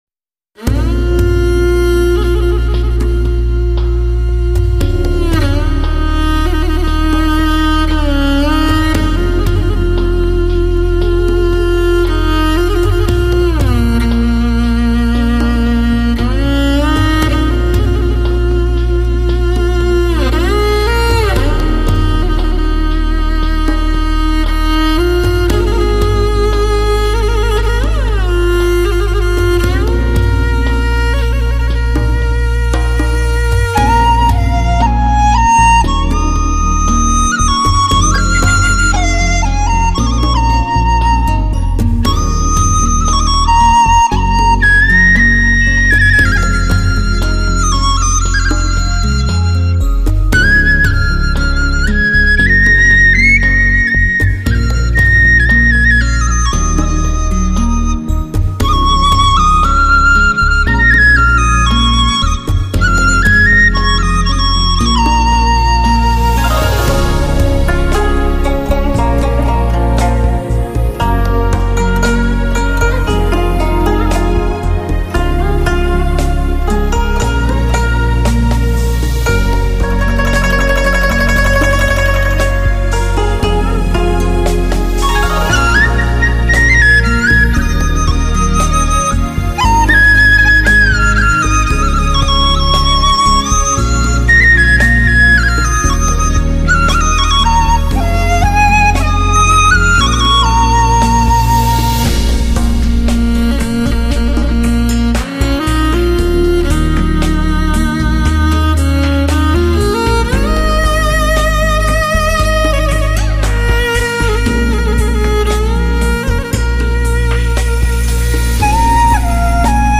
HD直刻无损高音质音源技术